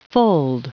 Prononciation du mot fold en anglais (fichier audio)
Prononciation du mot : fold